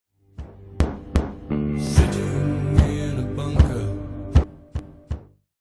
Genere: psychedelic rock